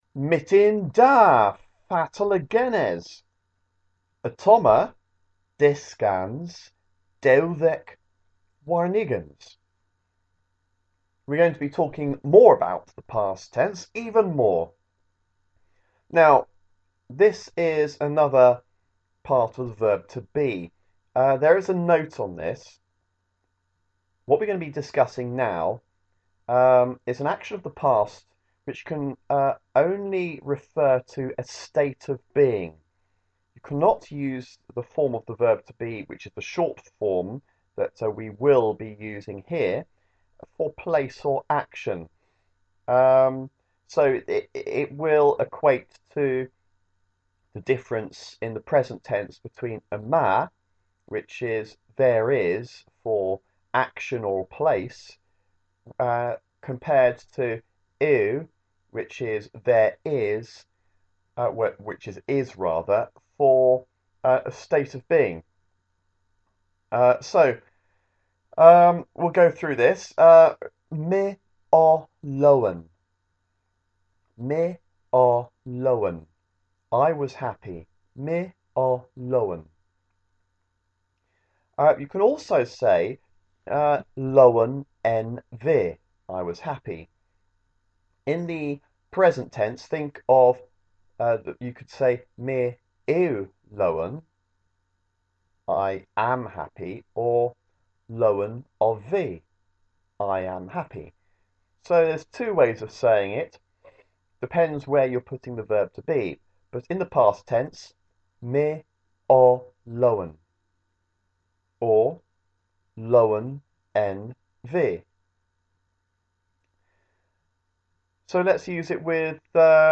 Cornish lesson 32 - Dyskans dewdhek warn ugens